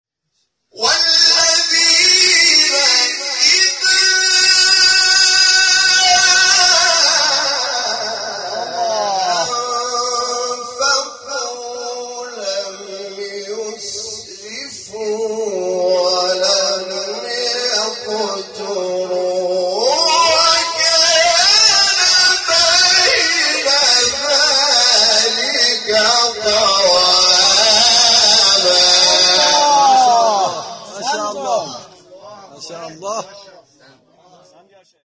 تلاوت آیه 67 سوره فرقان استاد حامد شاکرنژاد | نغمات قرآن
سوره : فرقان آیه : 67 استاد : حامد شاکرنژاد مقام : بیات قبلی بعدی